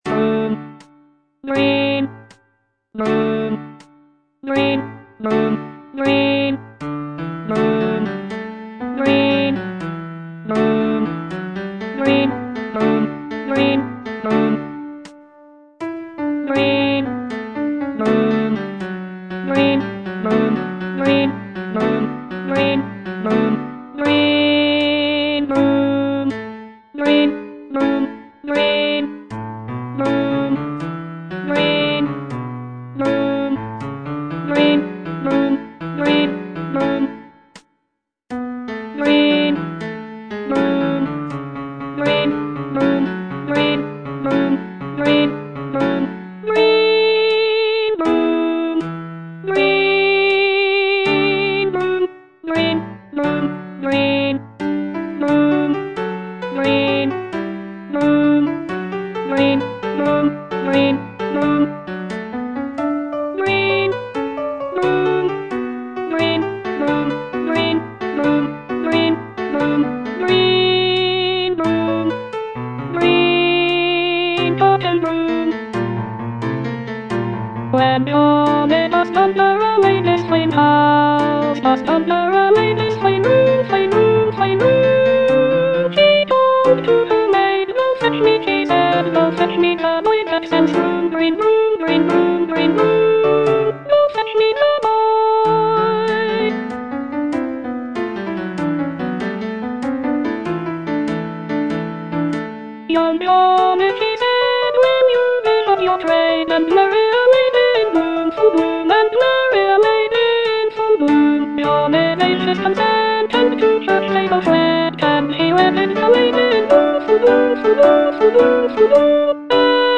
Alto I (Voice with metronome)